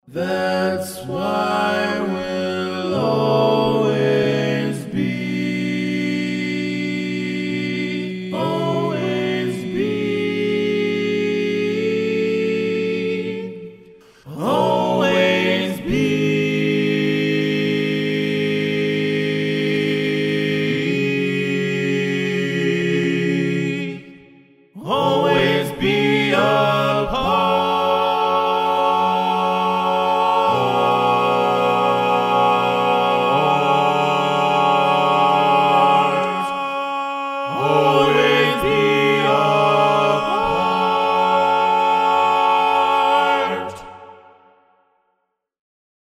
Key written in: F# Minor
Type: Barbershop